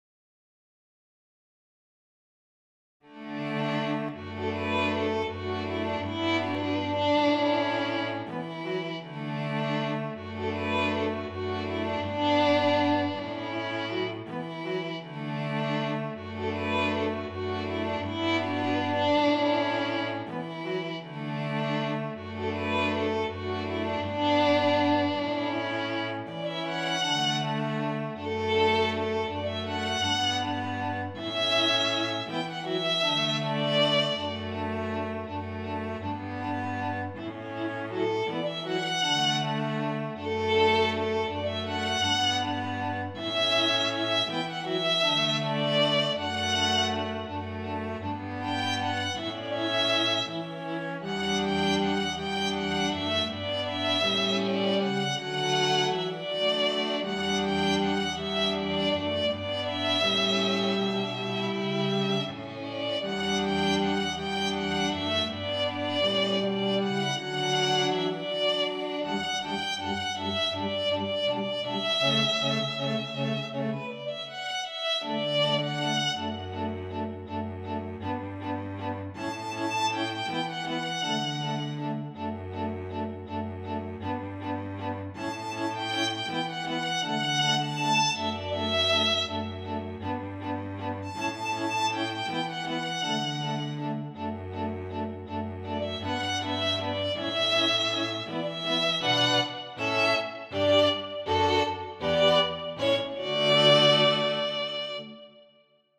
para Quarteto de Cordas, com a seguinte instrumentação:
● Violino I
● Violino II
● Viola
● Violoncelo